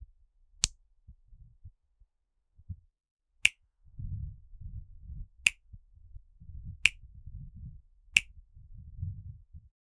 it a light breeze effect or comedic snap
it-a-light-breeze-effect-2fvi6jev.wav